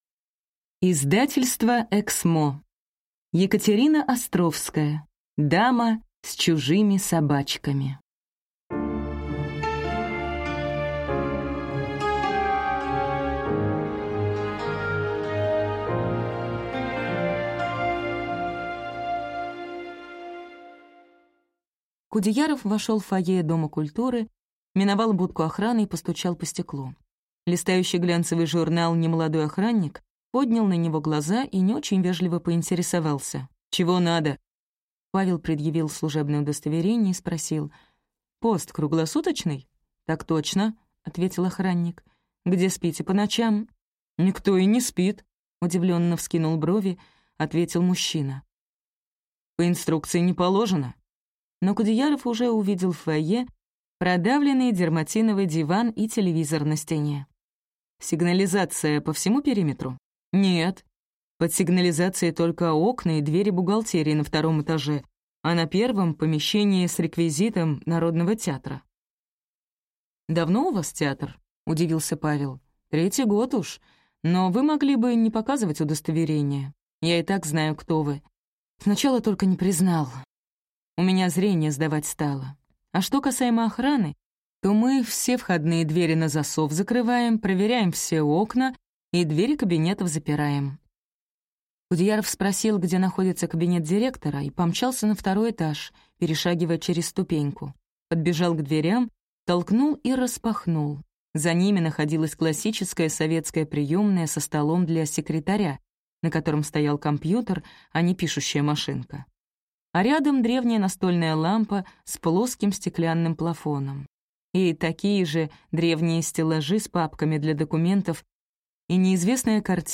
Аудиокнига Дама с чужими собачками | Библиотека аудиокниг